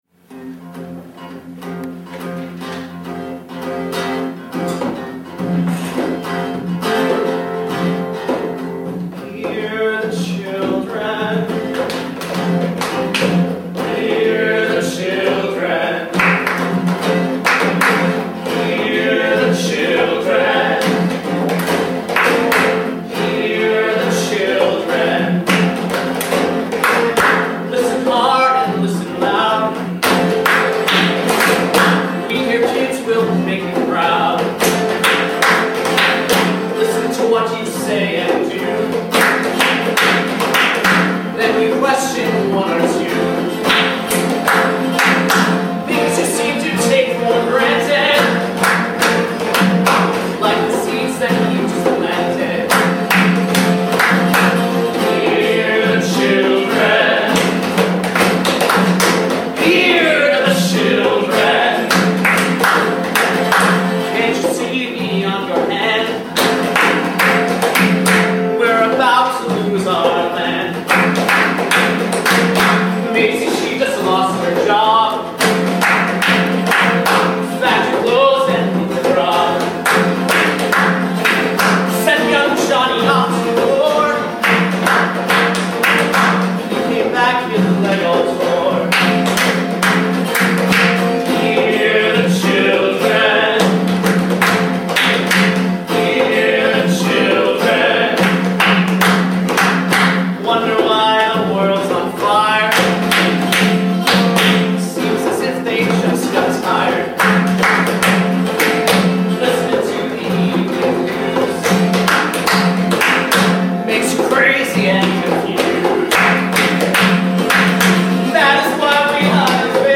The new ‘roots’ musical about community and family.